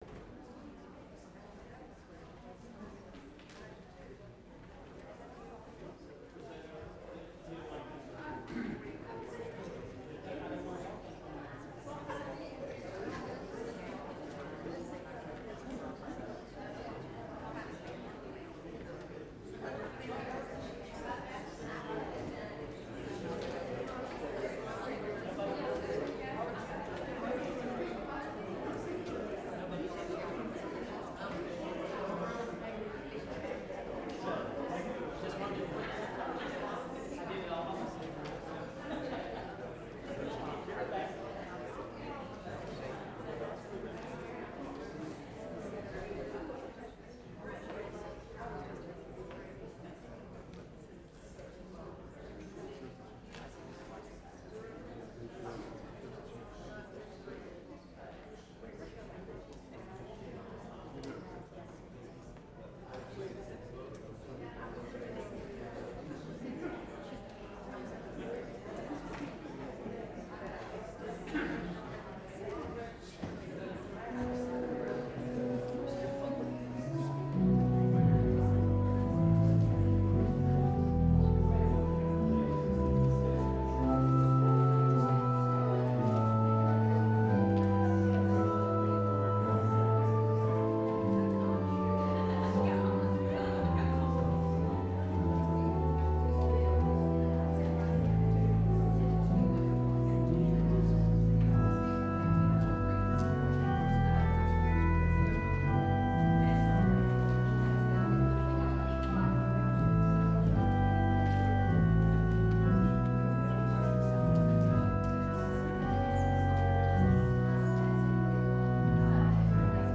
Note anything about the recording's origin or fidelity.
Good Friday Tenebrae Service